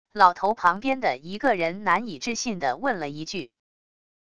老头旁边的一个人难以置信的问了一句wav音频